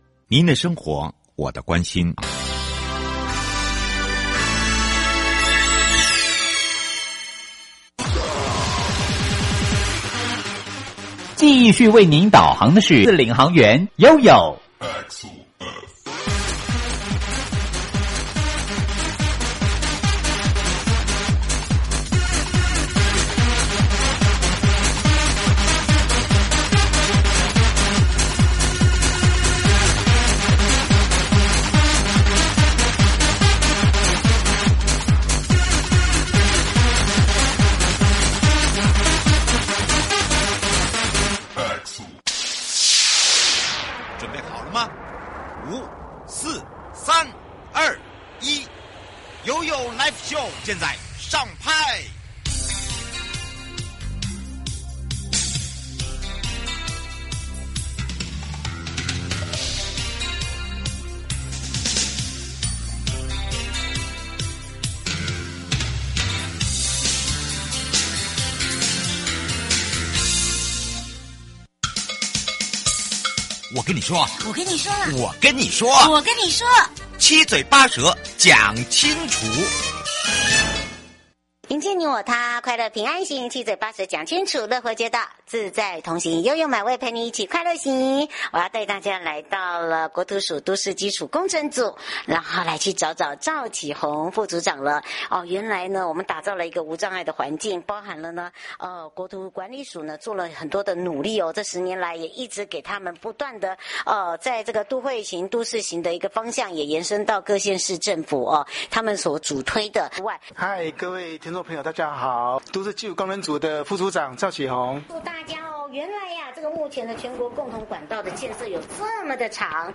受訪者： 營建你我他 快樂平安行~七嘴八舌講清楚~樂活街道自在同行!(二) 推動共同管道系統是維持路面平整度與通行品質的重要建設，不僅能整合公共設施管線，還可降低道路因管線施工挖掘次數，以減少對民眾生活通行的干擾，並維護都市美觀。